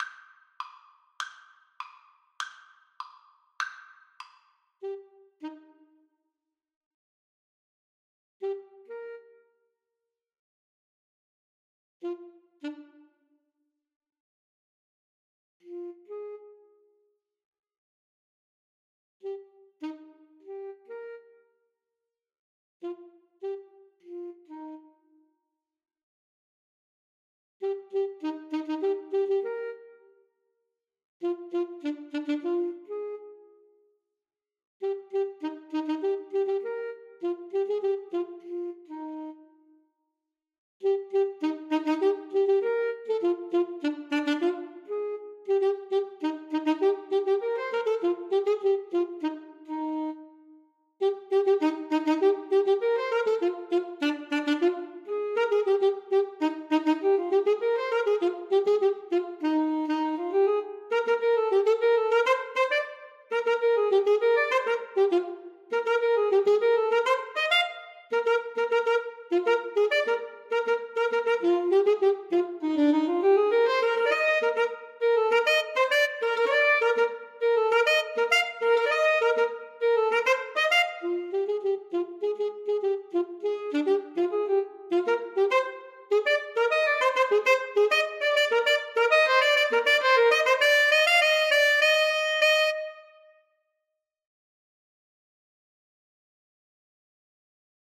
Alto Saxophone 1Alto Saxophone 2
2/4 (View more 2/4 Music)
Moderato e ritmico =c.100
Classical (View more Classical Alto Saxophone Duet Music)